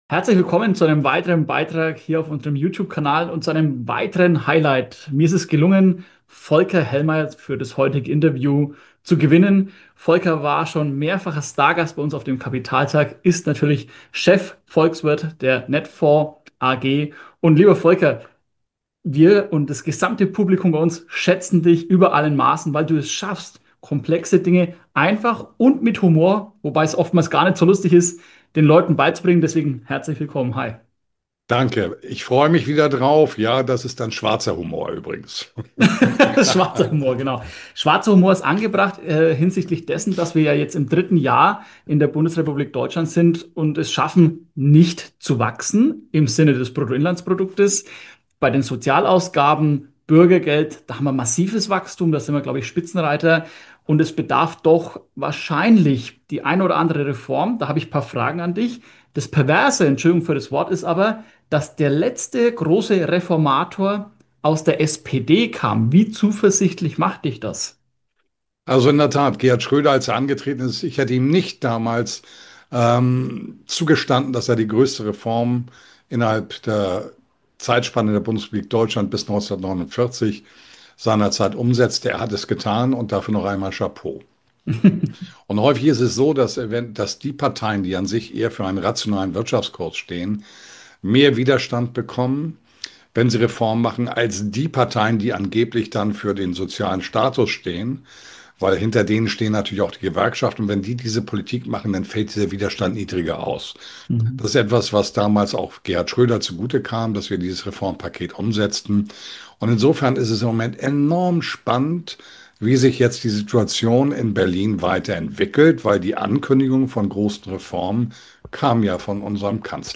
Der bekannte Volkswirt Folker Hellmeyer rechnet in diesem Interview schonungslos ab! Er analysiert den Aufstieg der AfD, die versteckte Schwäche des Euros und den unaufhaltsamen Vormarsch der BRICS-Staaten. Zum Schluss liefert er seinen radikalen 5-Punkte-Plan für Deutschlands Rettung.